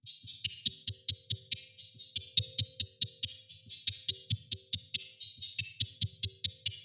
2. CAROUSEL CLICK LOOP.wav